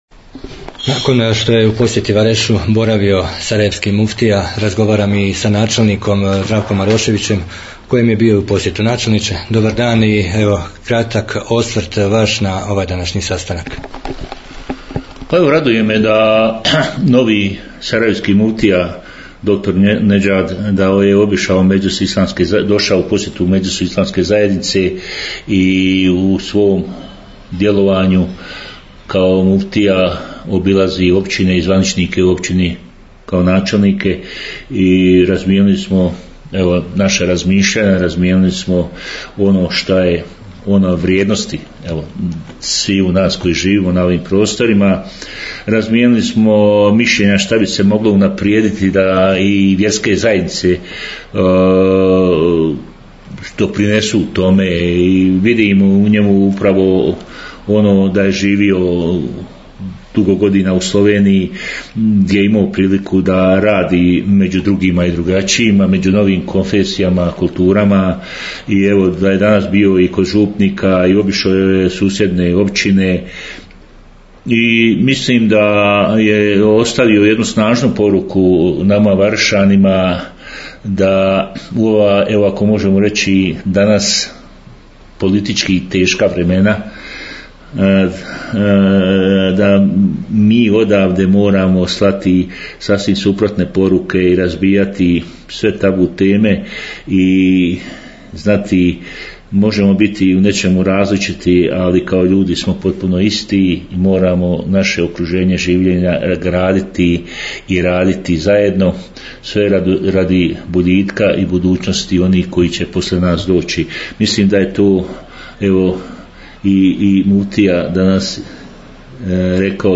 Izjave nakon sastanka sarajevkog muftije i načelnika Vareša
Poslušajte izjave sarajevskog muftije Nedžada efendije Grabusa i načelnika Maroševića nakon posjete Varešu ....